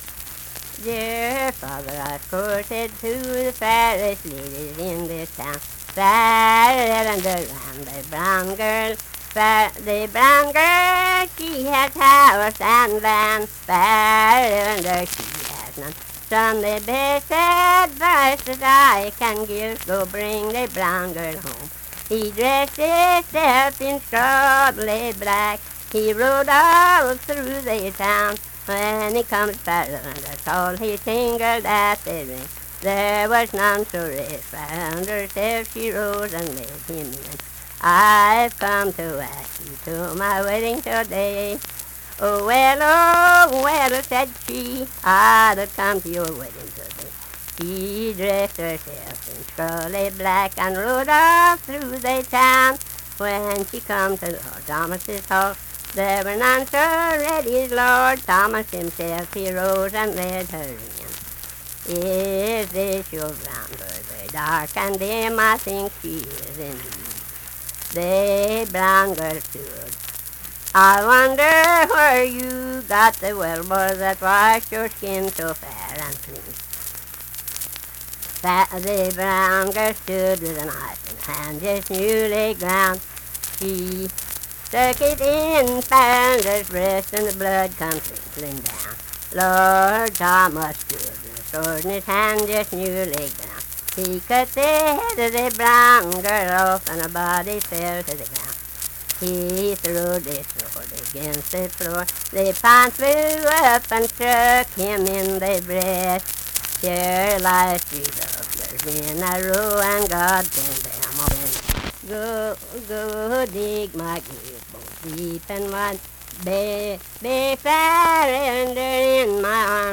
Unaccompanied vocal music
Voice (sung)
Logan County (W. Va.), Lundale (W. Va.)